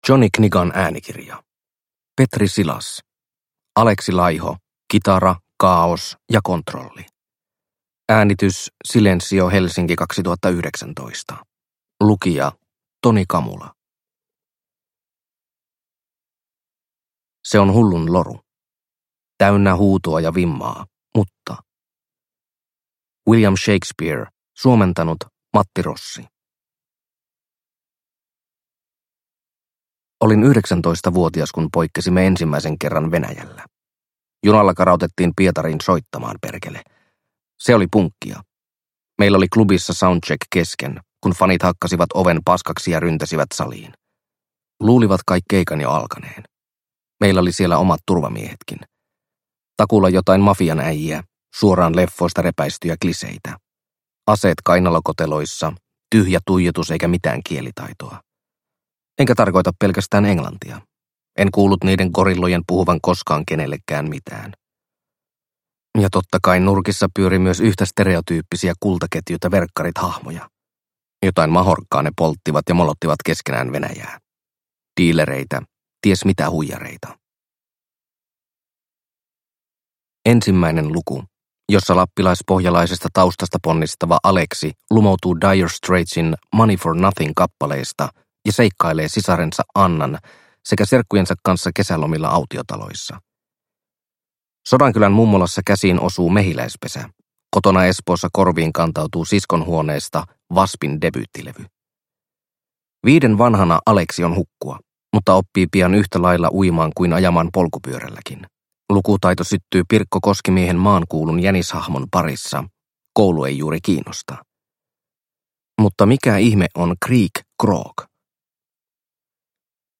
Alexi Laiho – Kitara, kaaos & kontrolli – Ljudbok – Laddas ner